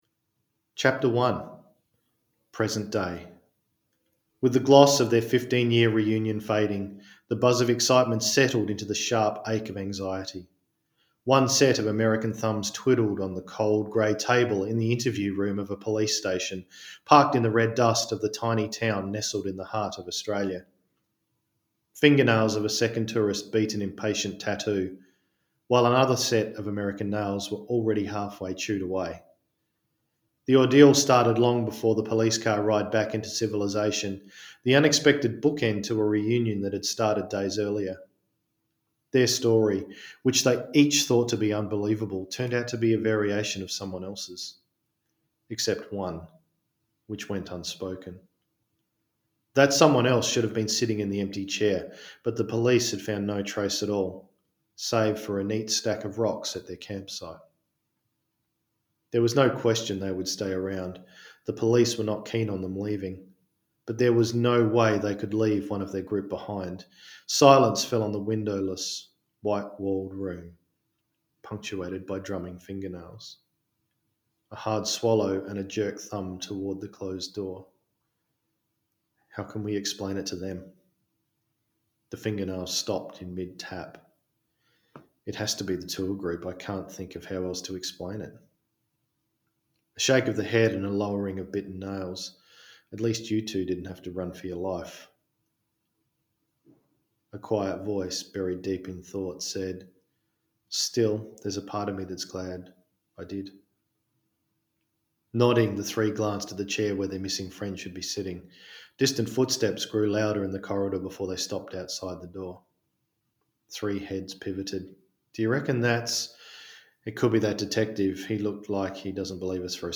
… both read by the author in his distinctive Aussie accent.